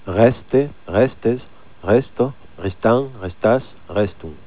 Conjugaison du verbe "resta" au pr�sent de l'indicatif : Rèste, rèstes, rèsto, restan, restas, rèston. . La prononciation du "s" de "restas" est facultative.